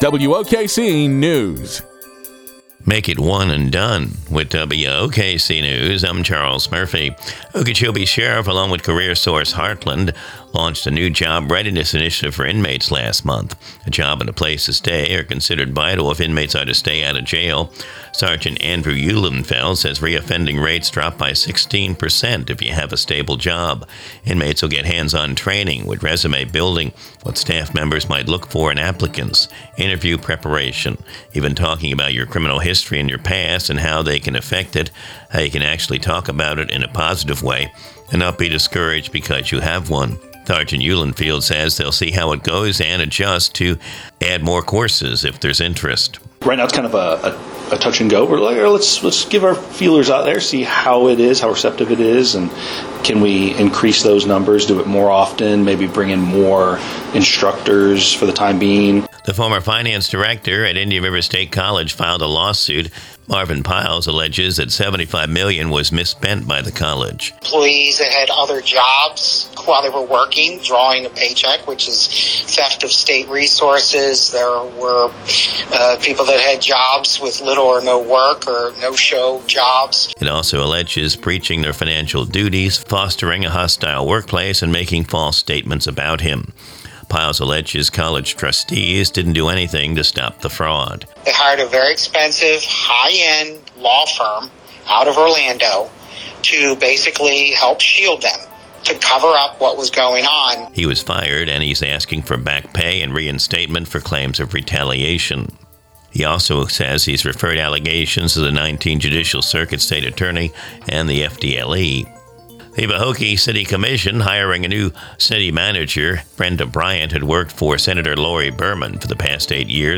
Recorded from the WOKC daily newscast (Glades Media).